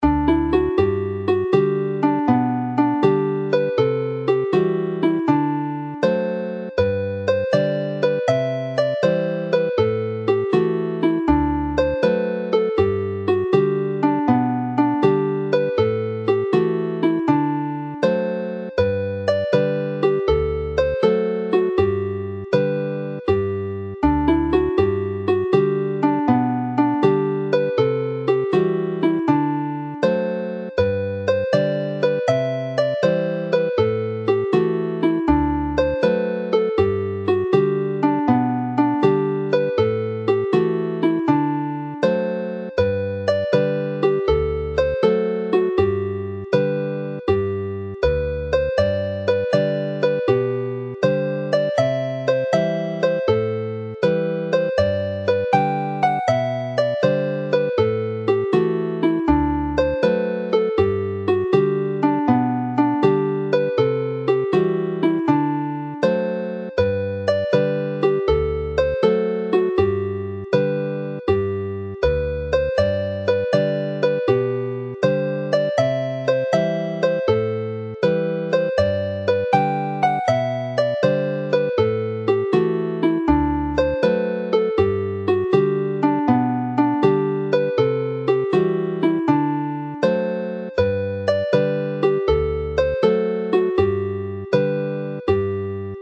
Chwarae'n araf
Play slowly